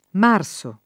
m#rSo] etn. stor. — anche in alcuni top. (Abr.): Magliano de’ Marsi; Gioia, Luco, Marano, Ortona, Rosciolo, San Benedetto dei Marsi; Lecce nei Marsi; e nel nome della diocesi dei Marsi ovvero di Avezzano — sim. il pers. m. stor. Marso